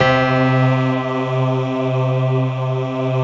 SI1 PIANO03R.wav